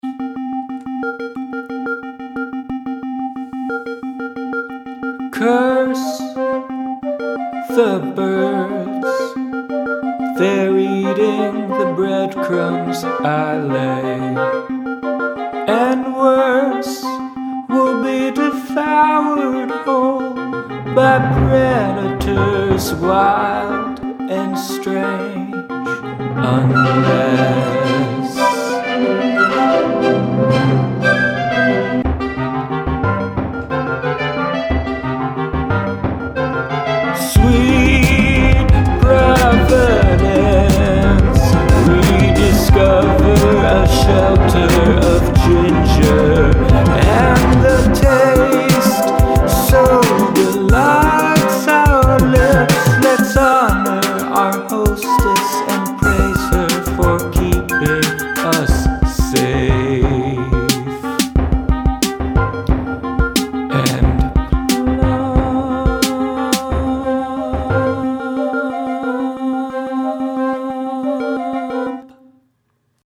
verse verse